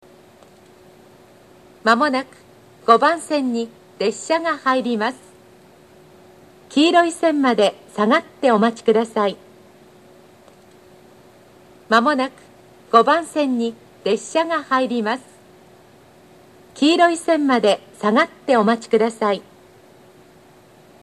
接近放送